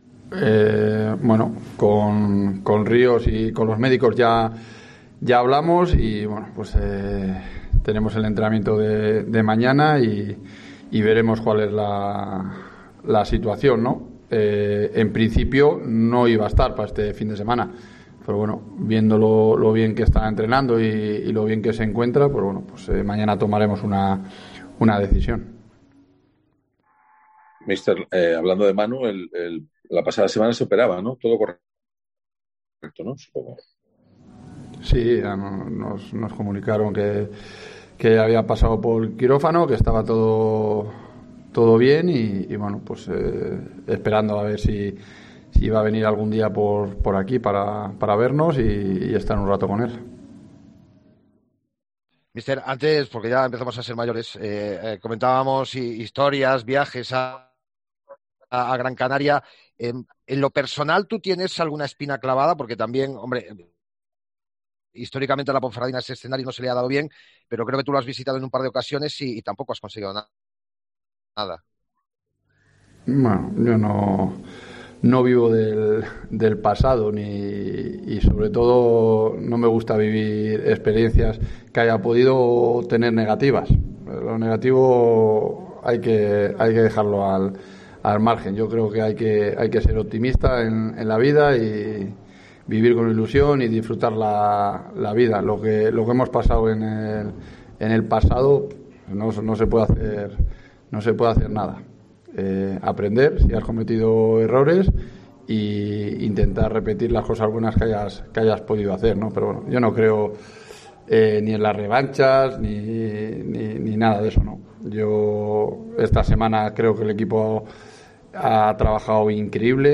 AUDIO: Escucha aquí las palabras del entrenador de la Ponferradina